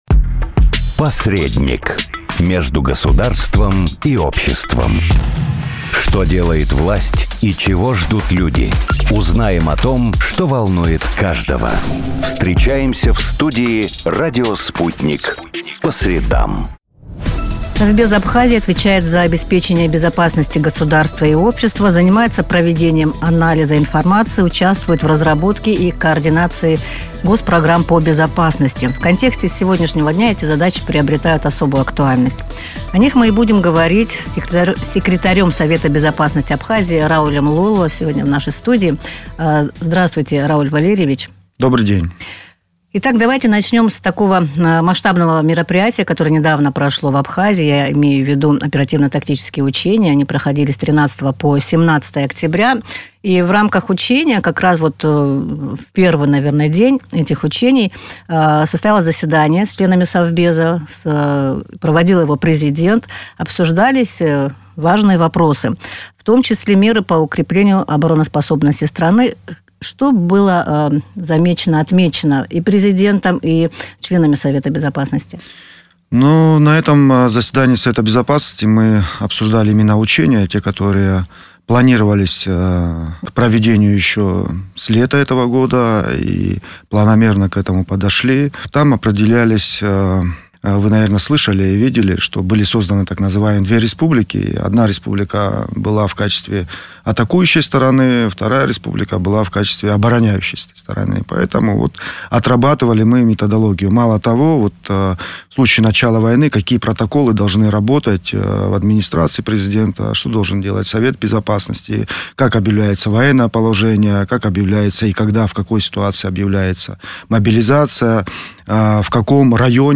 О безопасности страны в интервью с главой Совбеза